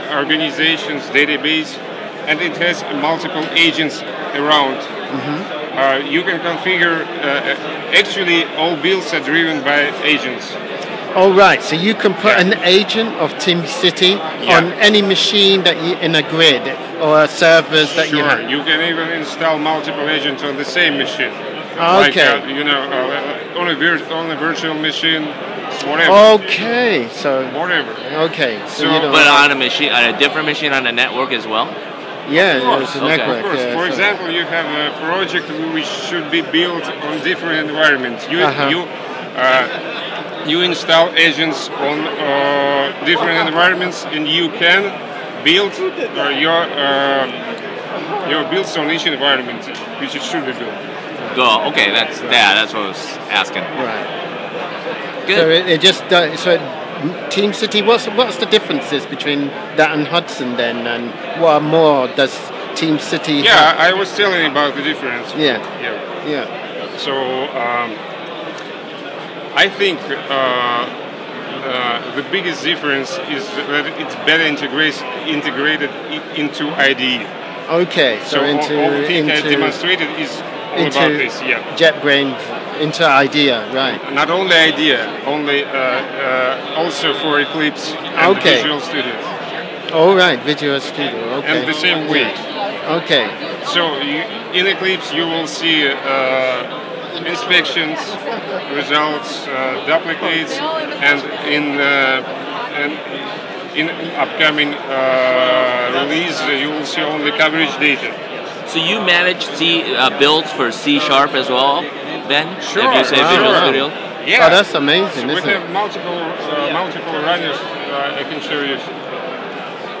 JavaOne 2010: Team City, JetBrains Booth, Exhibition Hall